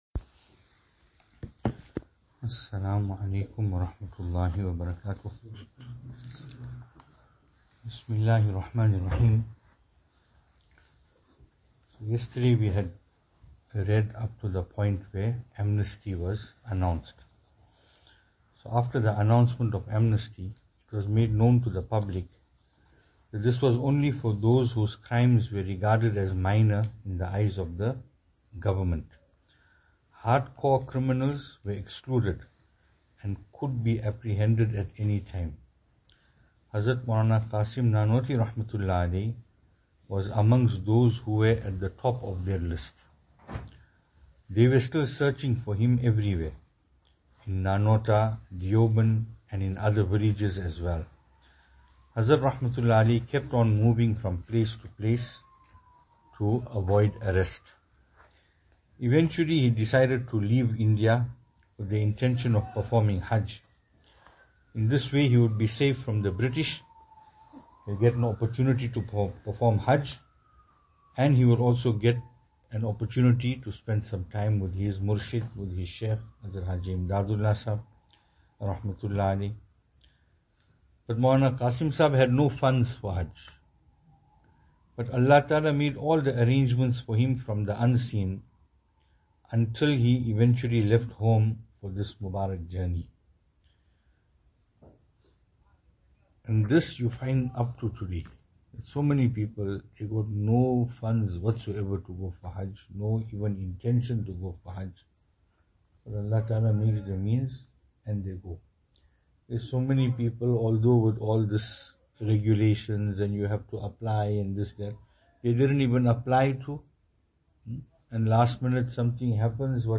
Venue: Albert Falls , Madressa Isha'atul Haq
Service Type: Ramadaan